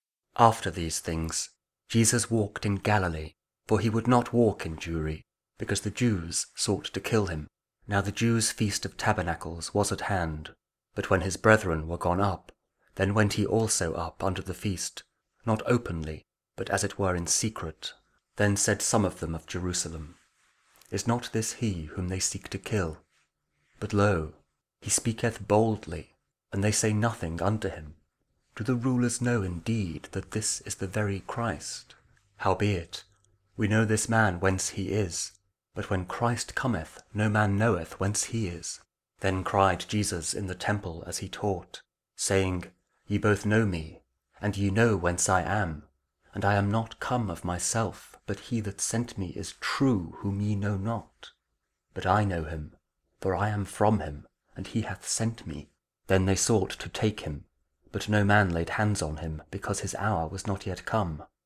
John 7: 1-2, 10, 25-30 – Lent Week 4, Friday (Audio Bible KJV, Spoken Word)